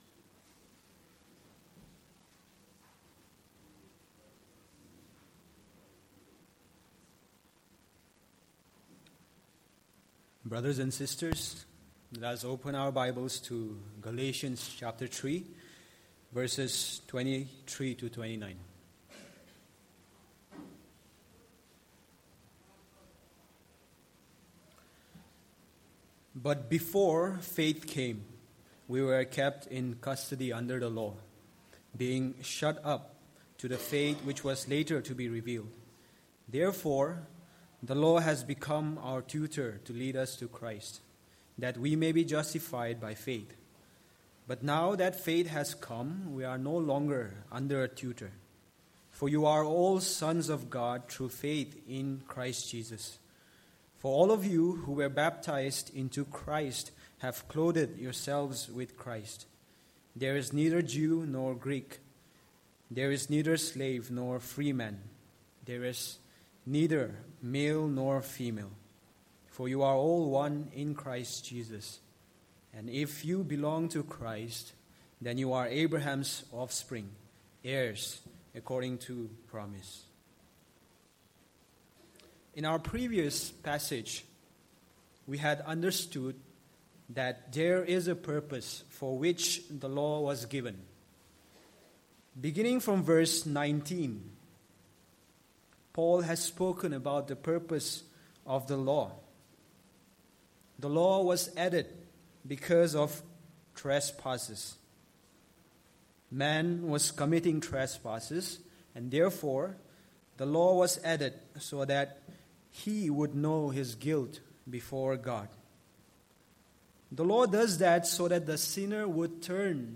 Passage: Galatians 3:23-29 Service Type: Sunday Morning